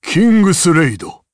Dakaris-Vox_Kingsraid_jp_b.wav